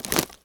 wood_tree_branch_move_13.wav